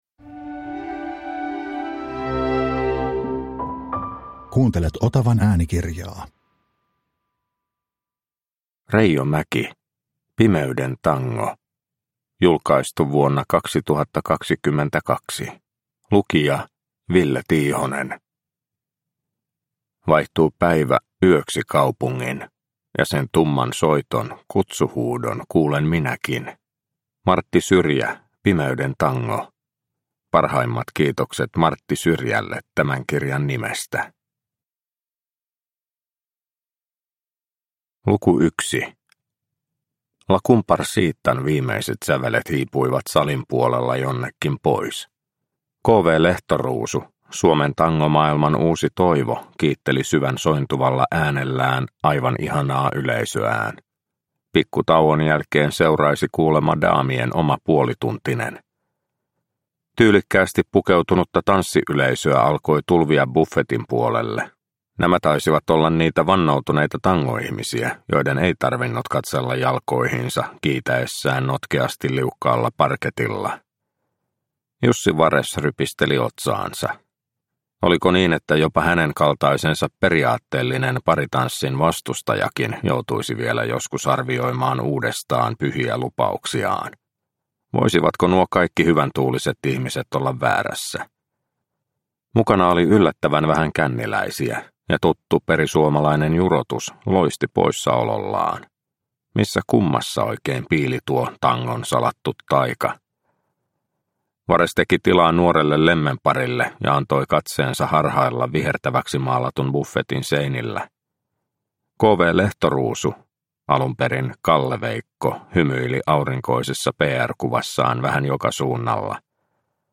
Pimeyden tango – Ljudbok – Laddas ner